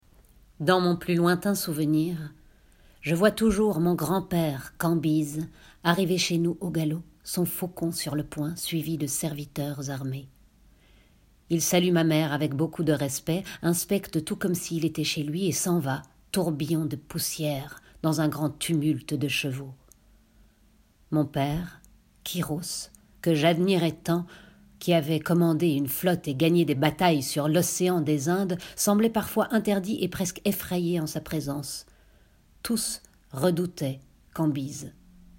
Voix off
extrait texte Diotime et les Lions
- Mezzo-soprano